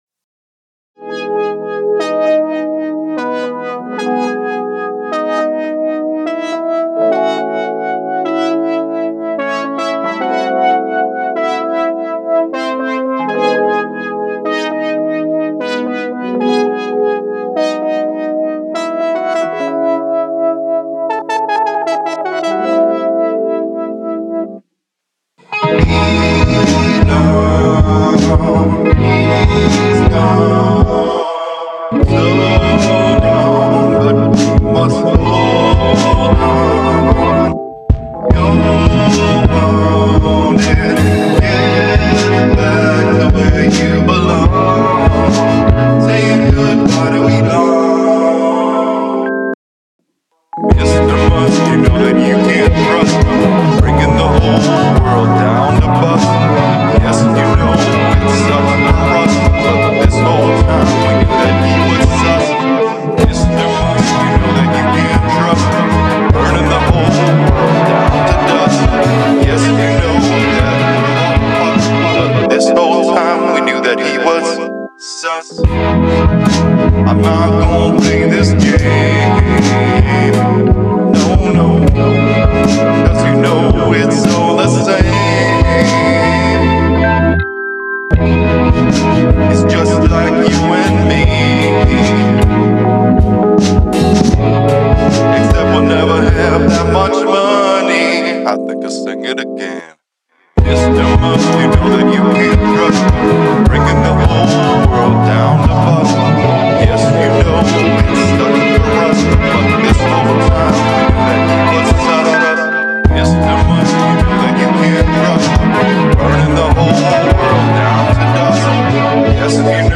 atmósferas alucinantes y cósmicas
la sensación de las melodías reverberantes
psicodelia